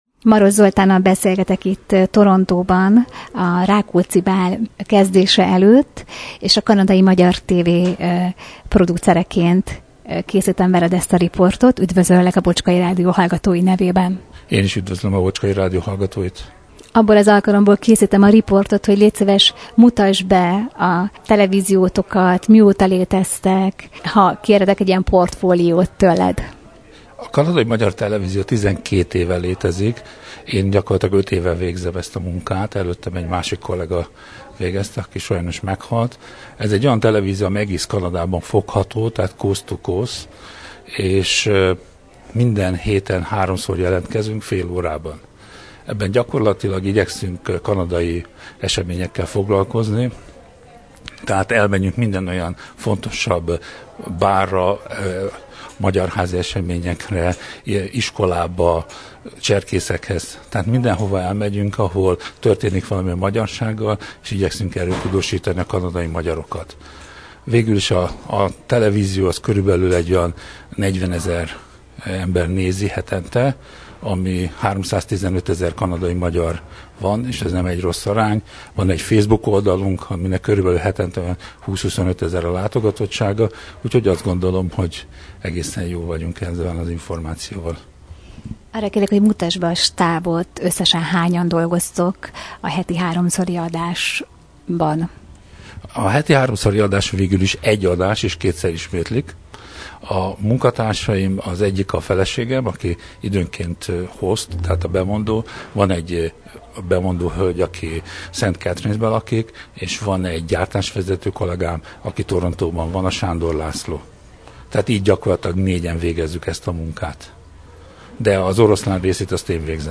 Találkozásunk alkalmával riportot készítettem vele is, hogy bemutassam a Kanadai Magyar Televíziót .